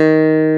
CLAV G2+.wav